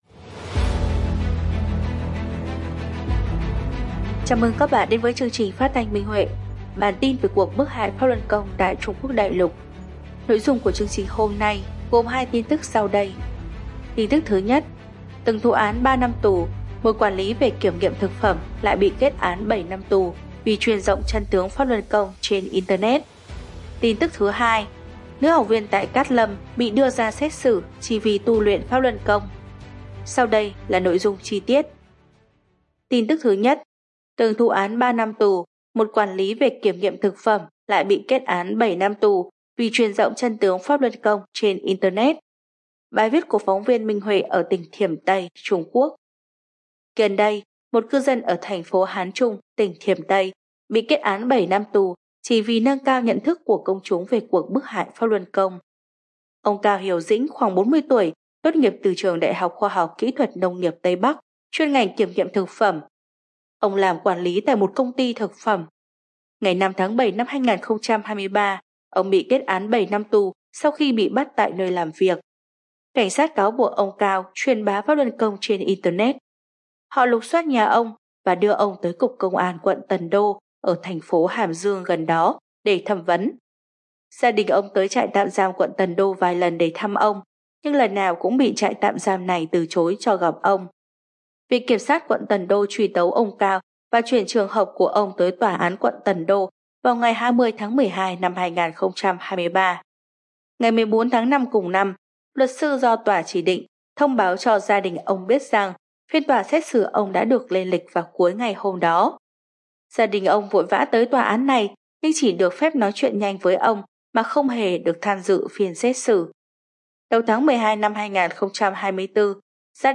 Chương trình phát thanh số 165: Tin tức Pháp Luân Đại Pháp tại Đại Lục – Ngày 19/12/2024